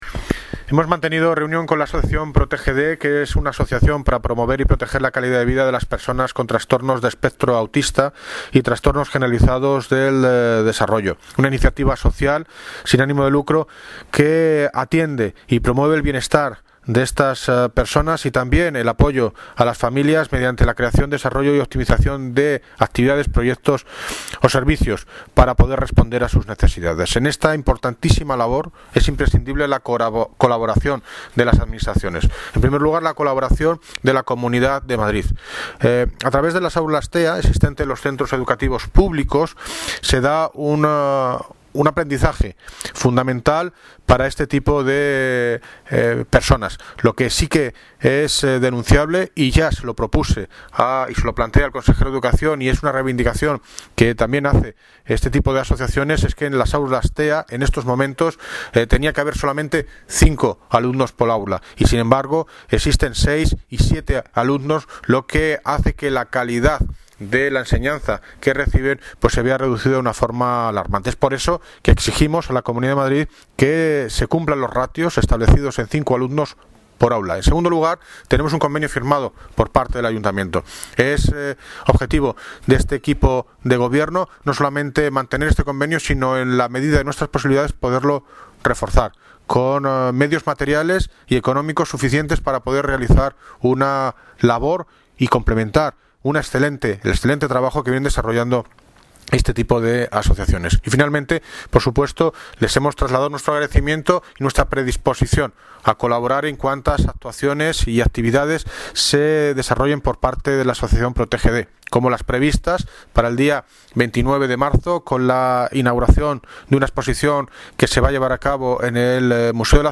Audio David Lucas (Alcalde de Móstoles) Sobre reunión Asociación PROTGD